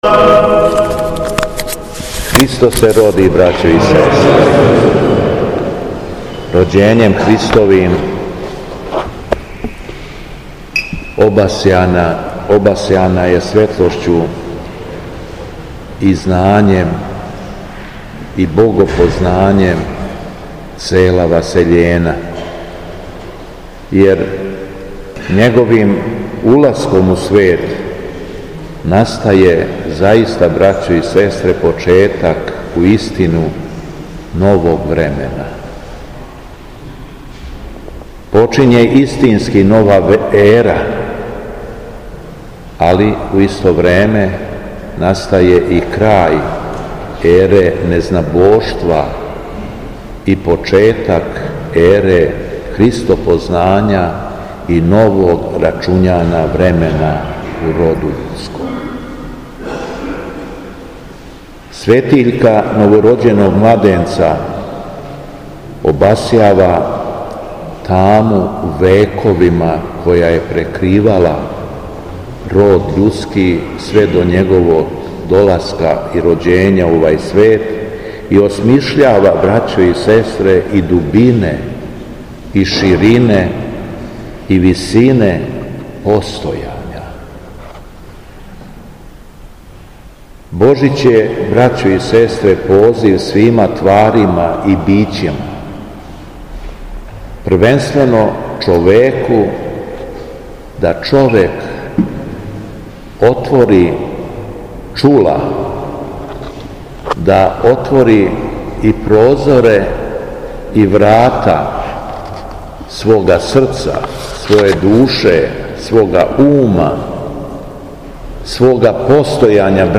У суботу по Божићу, 11. јануара 2025. године, Његово Високопреосвештенство Архиепископ крагујевачки и Митрополит шумадијски служио је Свету Литургију у Саборном храму Успења Пресвете Богородице у Крагујевцу.
Беседа Његовог Високопреосвештенства Митрополита шумадијског г. Јована
Након прочитаног зачала из Јеванђеља по Матеју, Митрополит Јован се свештенству и верном народу обратио беседом: